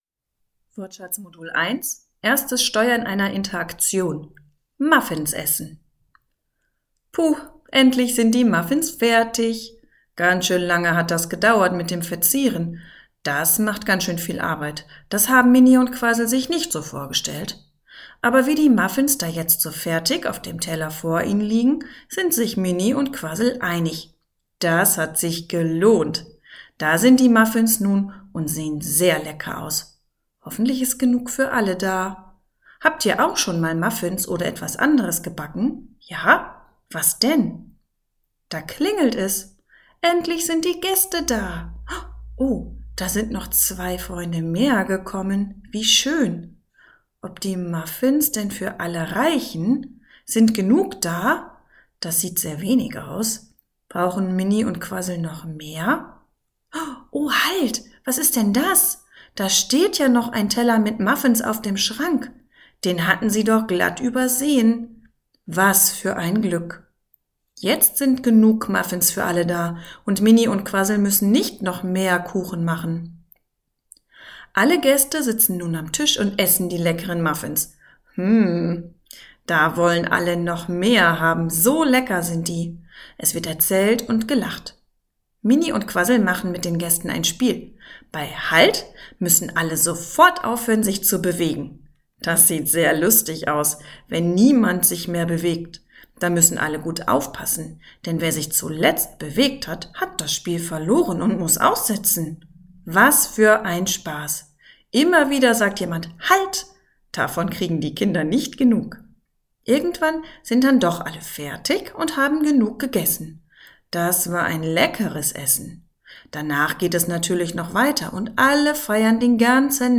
Die Aufnahmen sind mit viel Intonation und wörtlicher Rede eingesprochen, damit die Kinder ein lebhaftes Hörerlebnis haben.